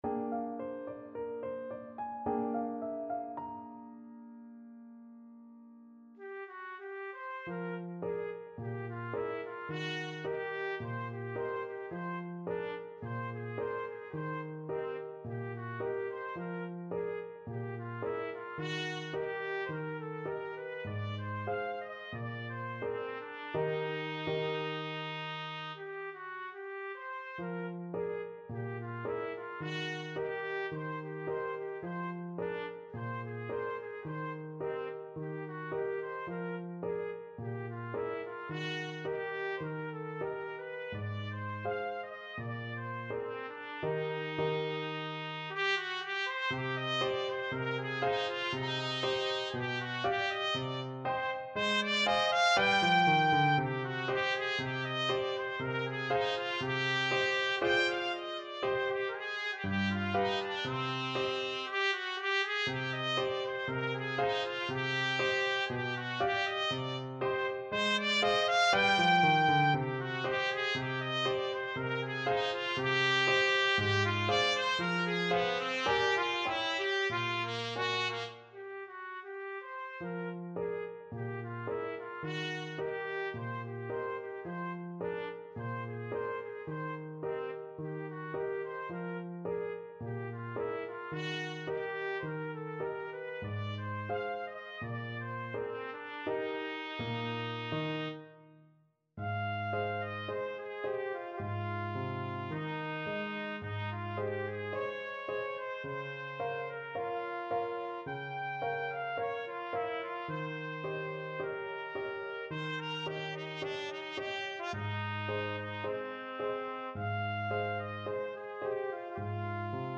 Trumpet version
4/4 (View more 4/4 Music)
Classical (View more Classical Trumpet Music)